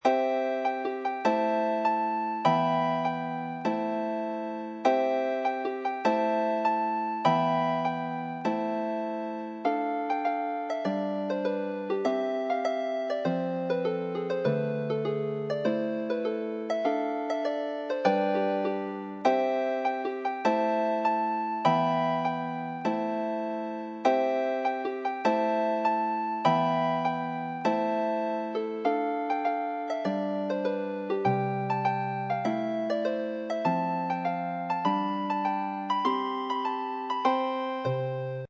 harp solo